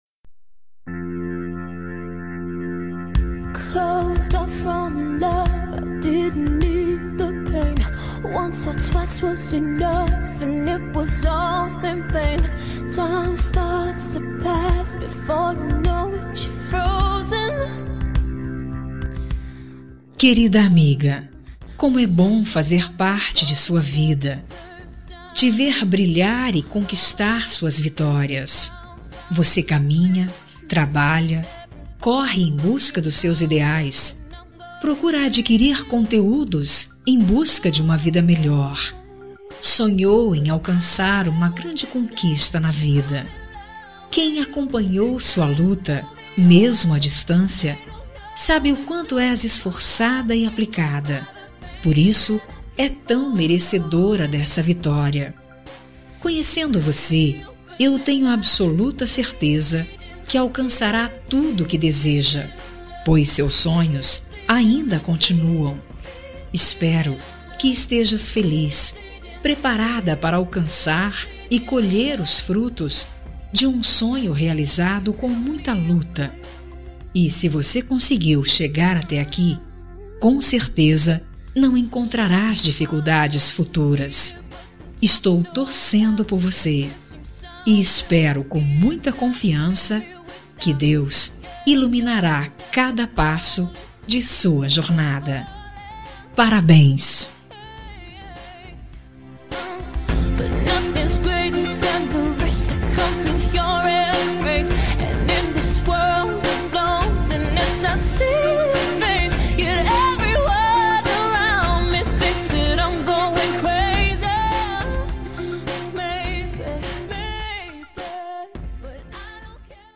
Telemensagem Aniversário de Amiga – Voz Feminina – Cód: 202059